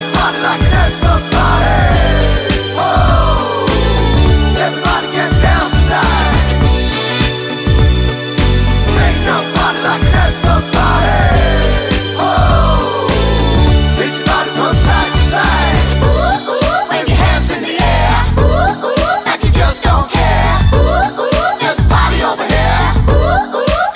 No Chorus Backing Vocals Pop (1990s) 3:32 Buy £1.50